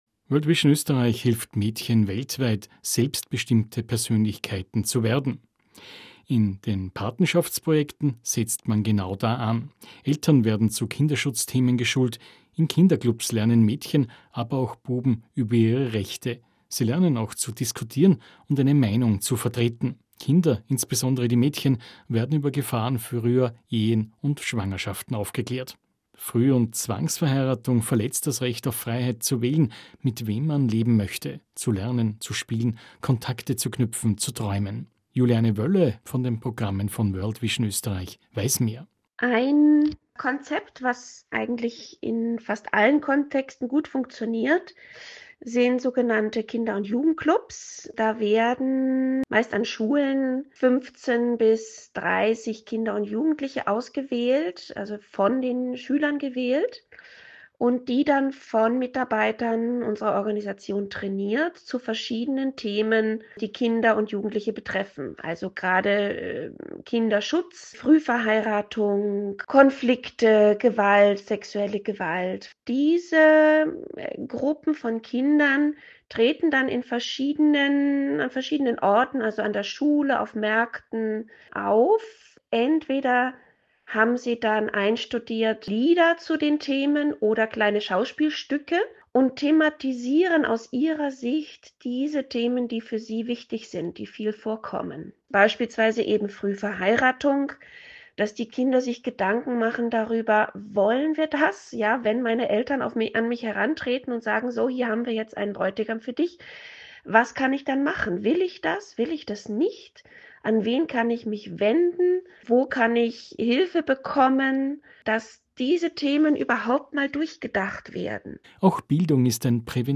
Radiobeiträge zu unserer „100 Mädchen“ Kampagne